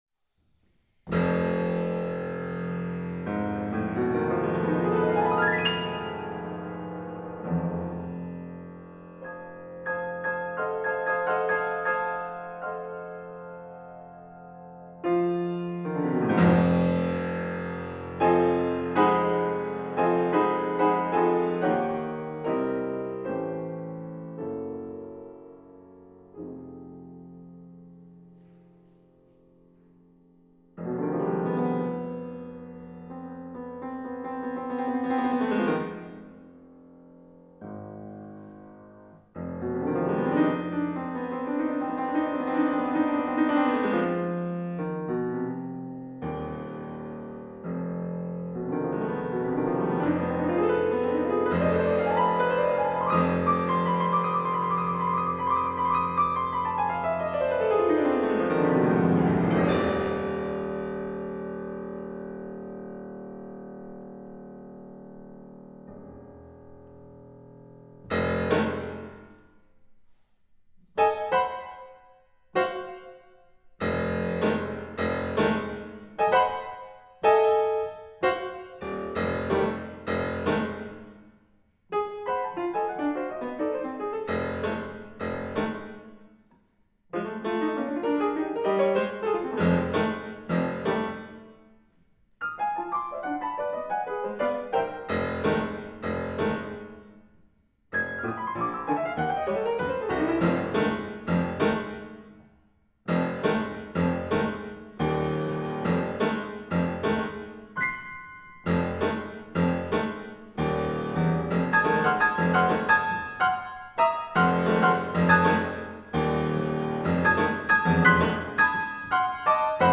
Genre: Solo Piano
Set in three contrasting movements